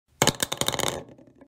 plasticpipe.mp3